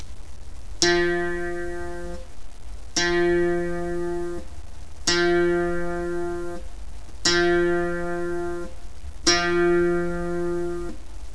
El sonido de cada cuerda afinada deberia de sonar asi:
Primera(Fa)
afinacion_fa.wav